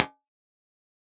Usage note: Dry impulses are in mono, wet "Air" impulses stereo.
Mic "B" (Telefunken U47) Dry
RammCabBWet.wav